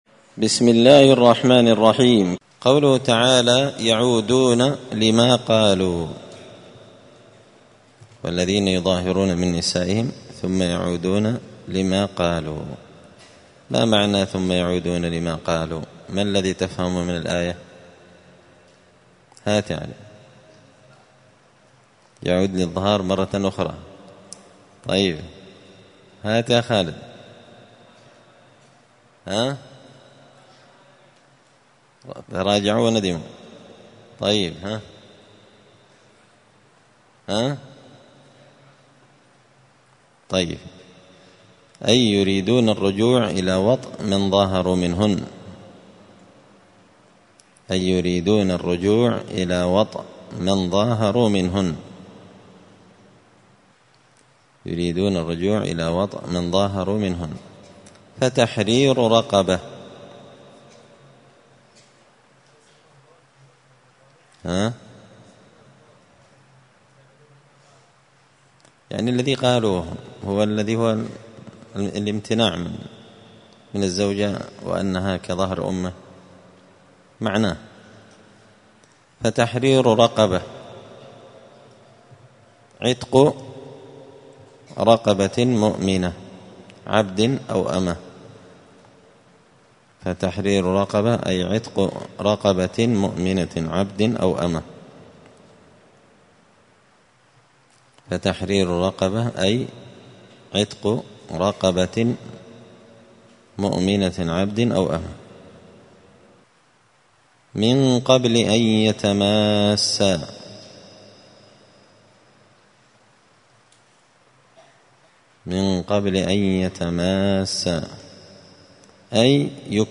*(جزء المجادلة سورة المجادلة الدرس 121)*